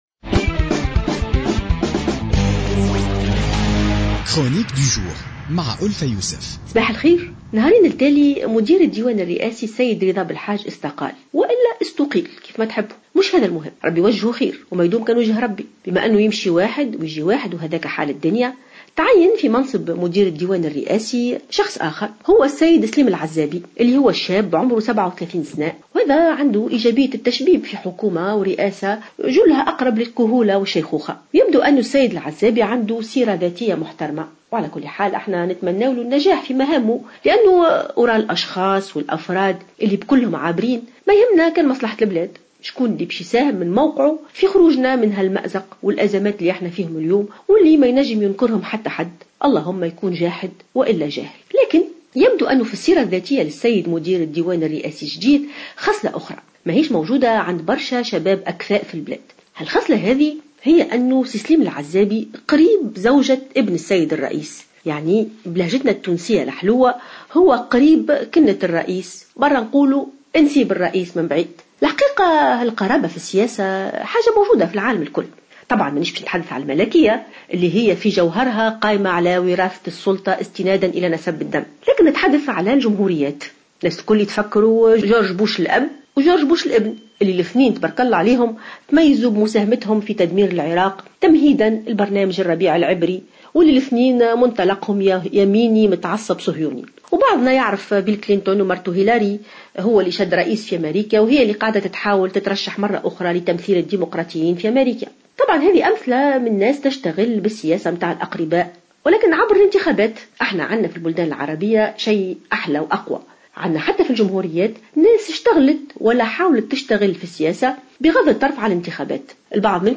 تحدثت الأستاذة الجامعية ألفة يوسف في افتتاحية اليوم الأربعاء 03 فيفري 2016 عن تعيين سليم العزابي في منصب رئيس ديوان رئيس الجمهورية خلفا لرضا بلحاج الذي استقال أو أقيل وفق قولها.